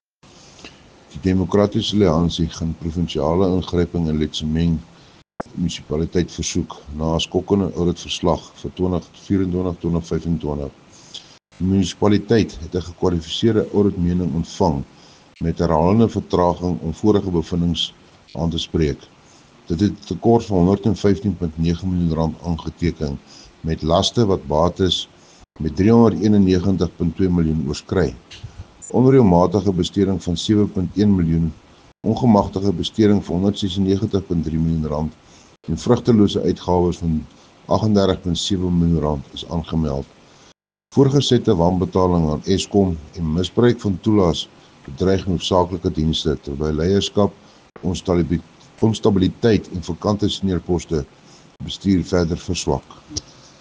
Afrikaans soundbite by Cllr Johann Steenkamp